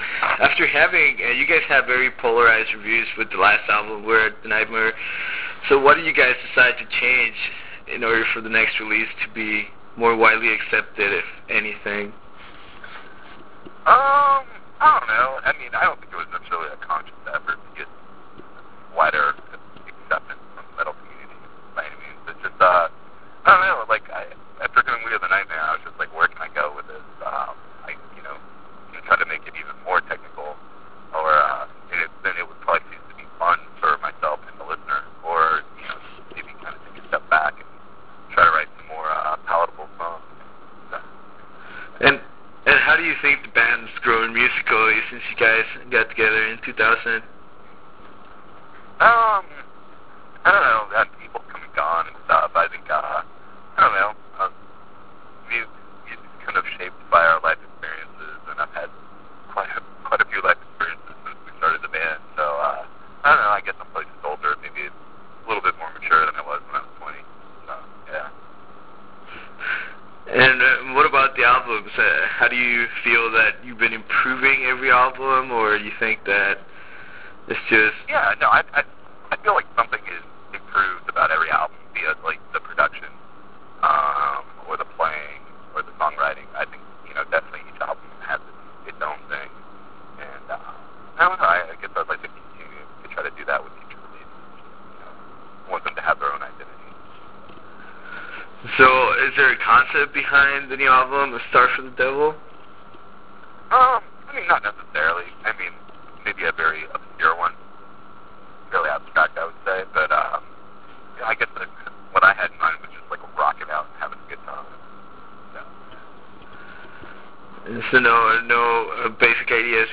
Interview with Arsis